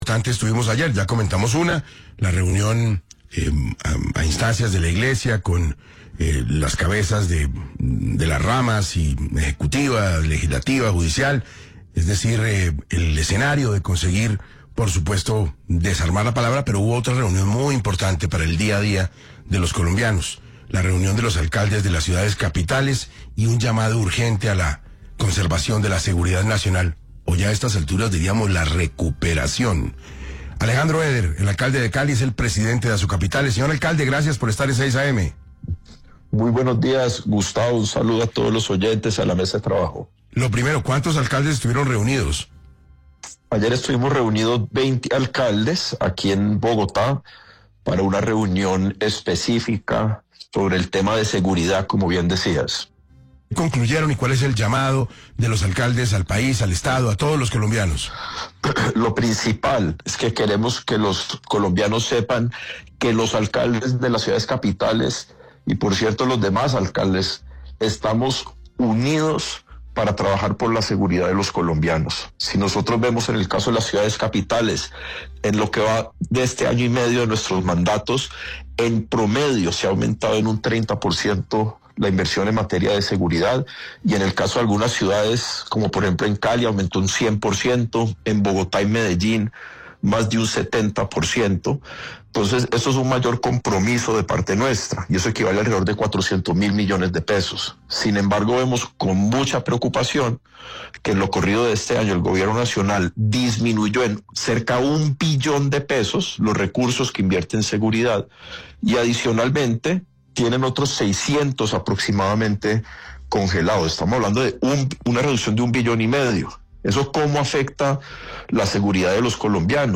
En una entrevista con 6AM de Caracol Radio, Alejandro Eder, alcalde de Cali y presidente de Asocapitales, expresó su profunda preocupación por la reducción de los recursos que el Gobierno Nacional ha destinado este año a la seguridad ciudadana.